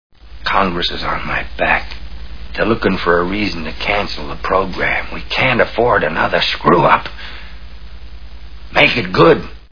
Capricorn One Movie Sound Bites